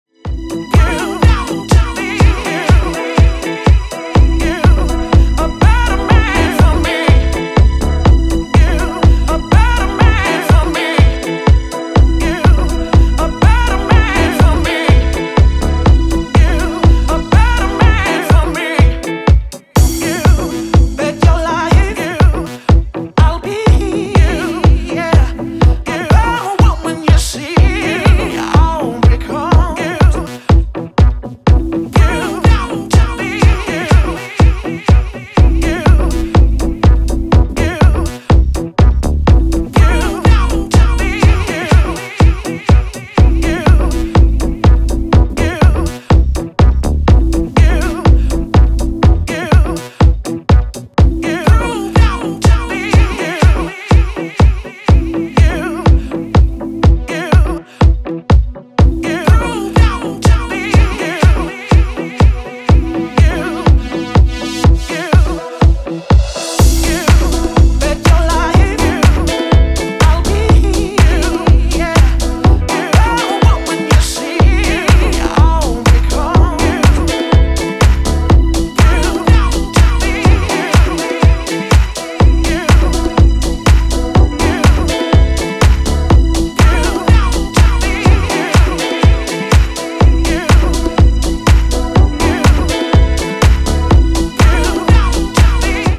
future house classic